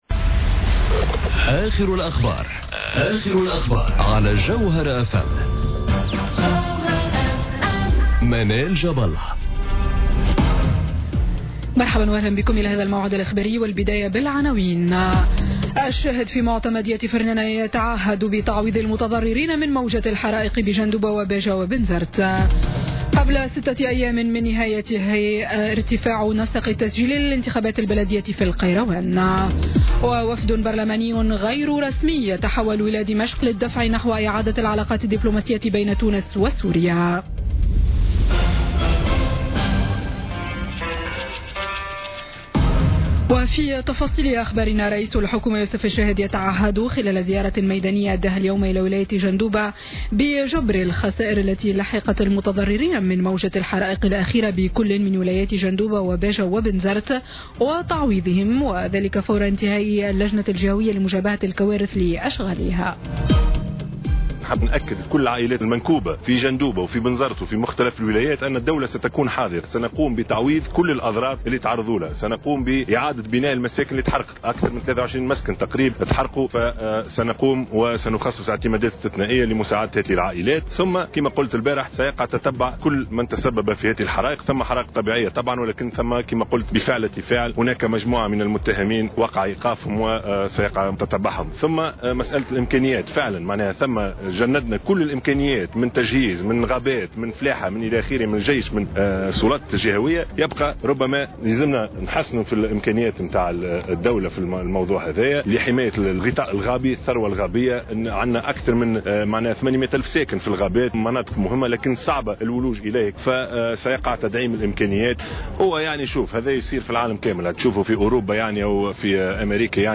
نشرة أخبار السابعة مساء ليوم الجمعة 4 أوت 2017